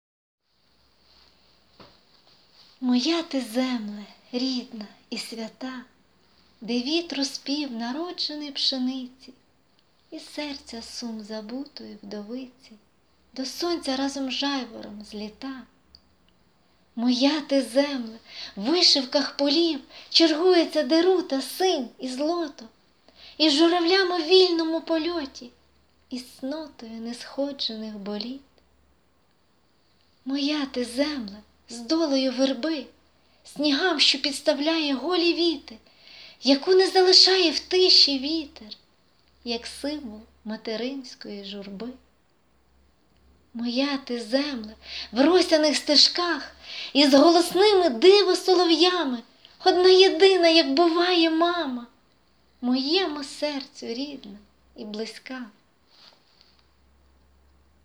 Рубрика: Поезія, Лірика
12 16 Приємний голос і виразна й інтонаційна декламація.